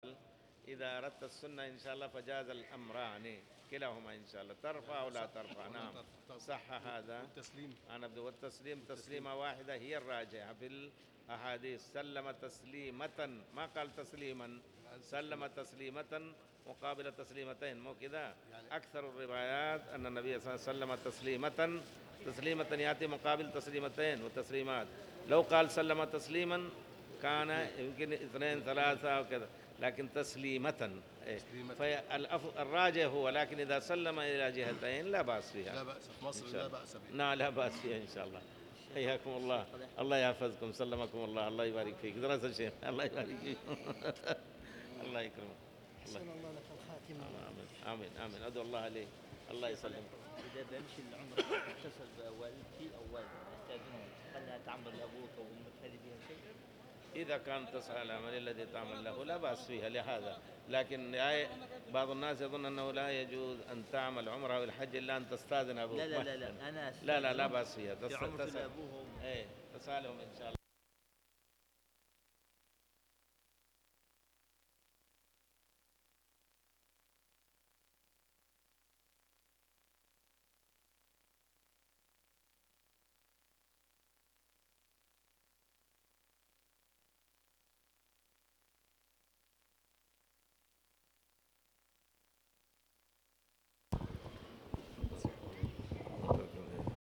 تاريخ النشر ١٩ ذو الحجة ١٤٣٨ هـ المكان: المسجد الحرام الشيخ